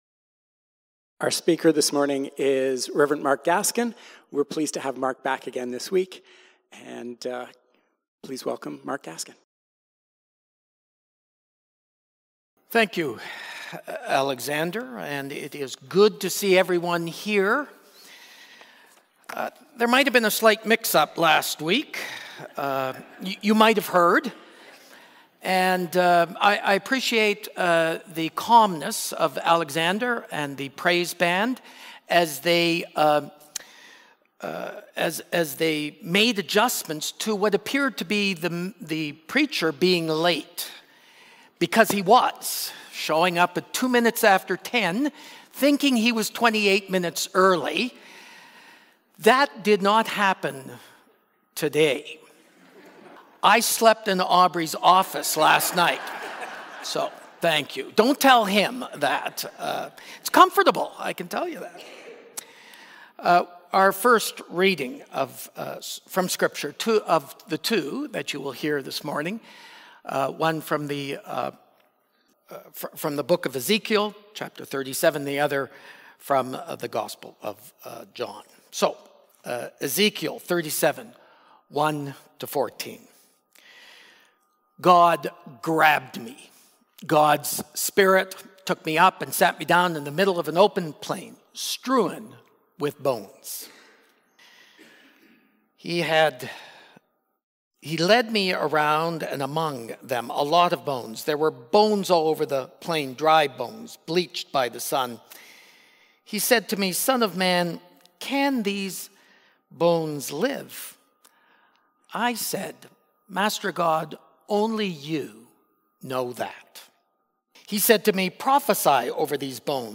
March-22-Sermon.mp3